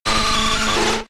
Cri de Nosferapti K.O. dans Pokémon X et Y.